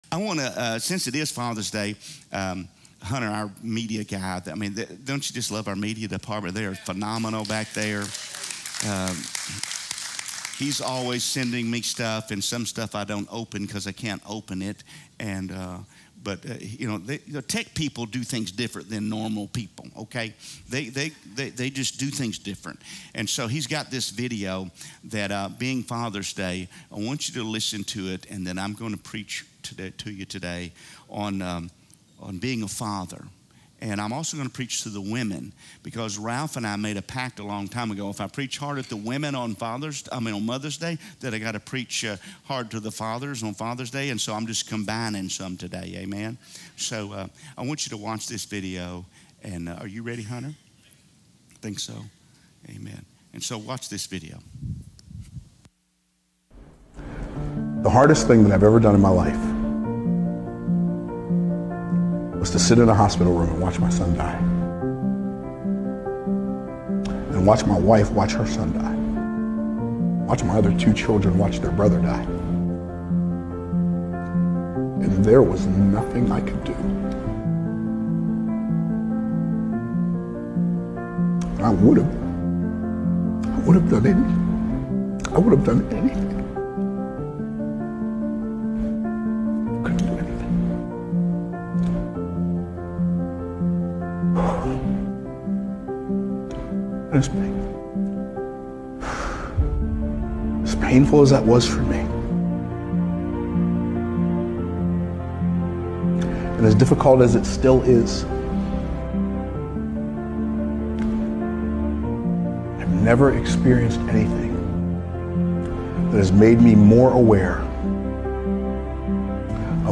From Series: "Sunday Message"